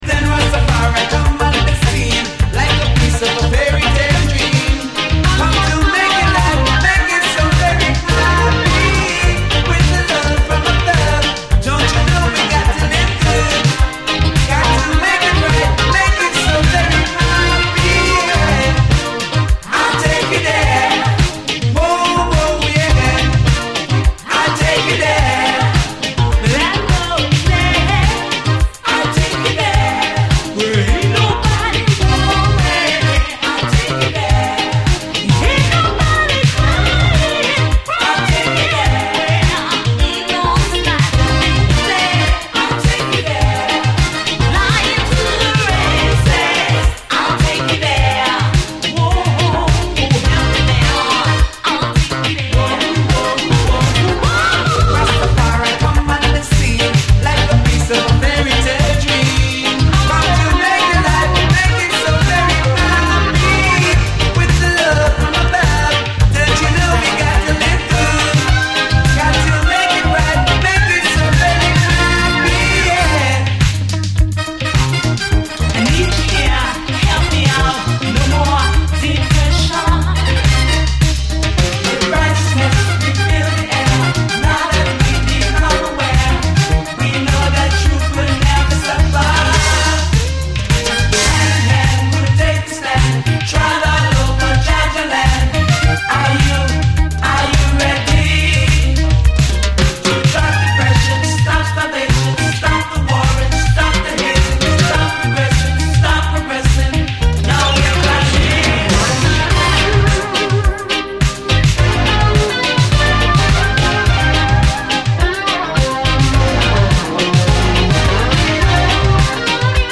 えも言われぬ魅惑のグルーヴが充満！
シンセ・ソロ、ダビーなエフェクト＆展開が素晴らしく、このインスト・ヴァージョンも超最高です！